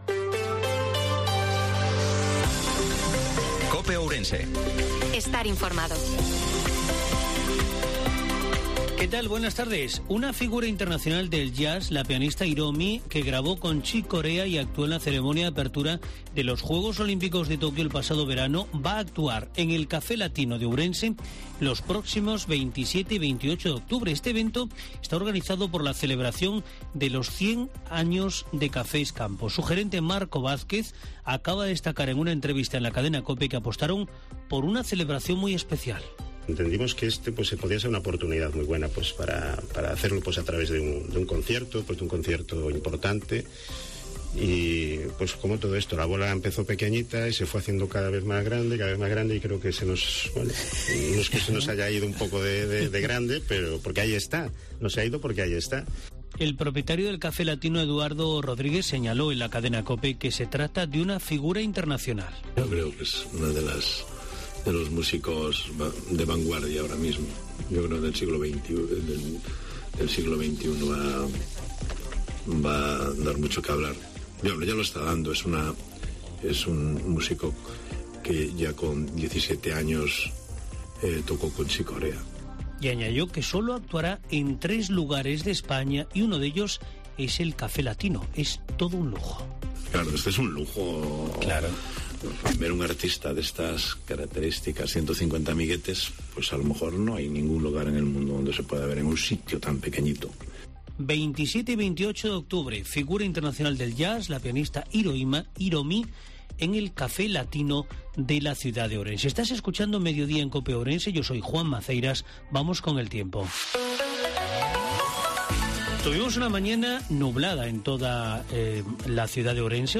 INFORMATIVO MEDIODIA COPE OURENSE-14/10/2022